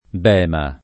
bema [ b $ ma ] s. m. (archeol.); pl. bemi